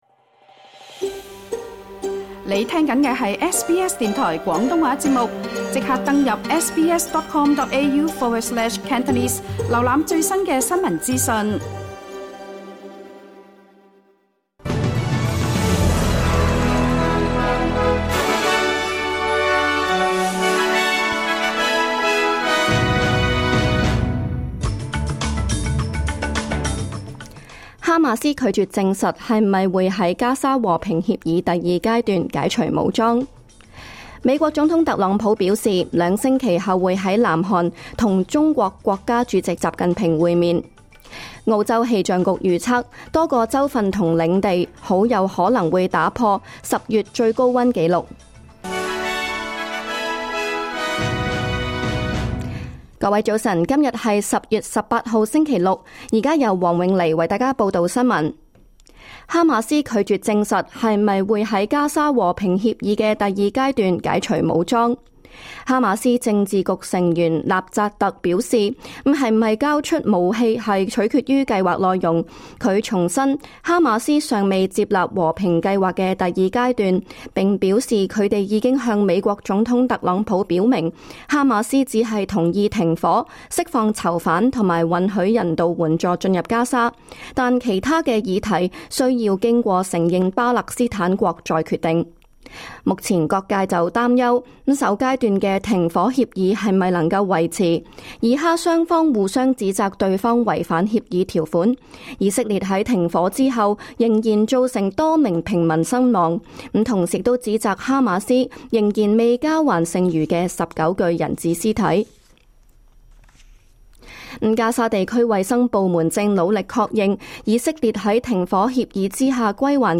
2025 年 10 月 18 日 SBS 廣東話節目詳盡早晨新聞報道。